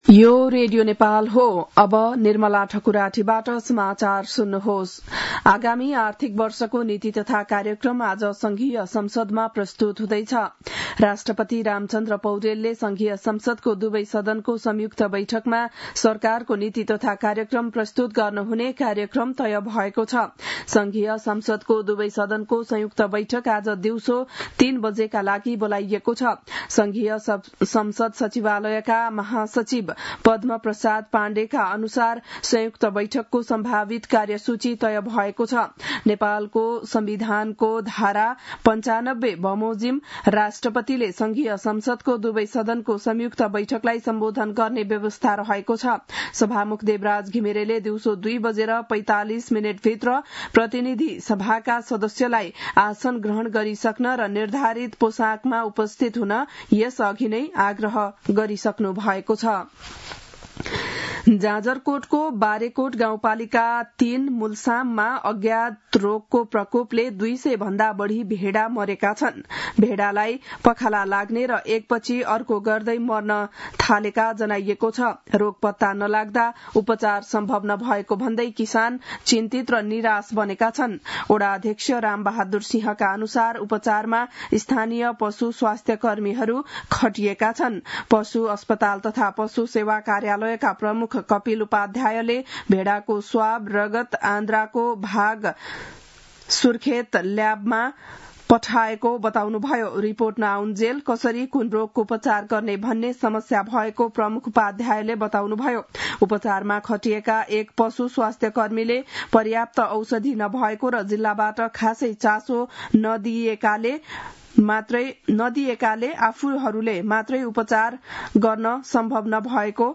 बिहान ११ बजेको नेपाली समाचार : १९ वैशाख , २०८२
11-am-news-.mp3